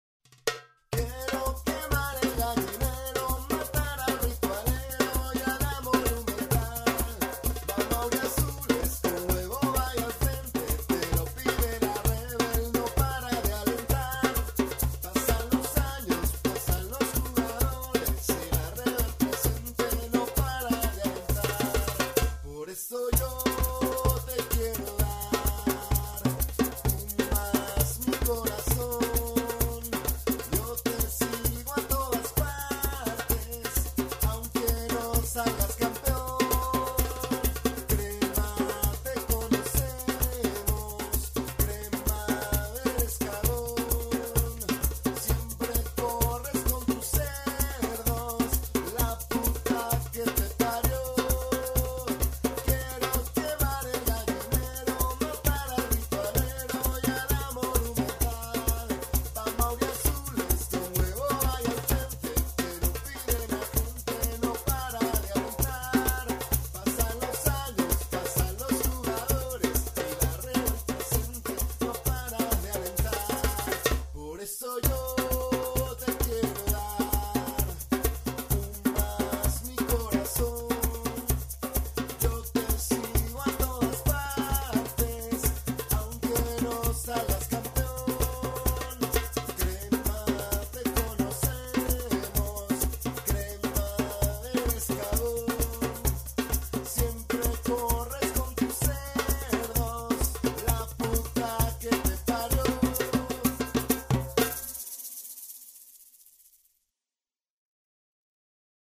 ahi les dejo un mp3 con la maqueta